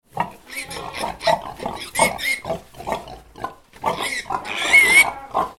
Pig-noise-sound-effect.mp3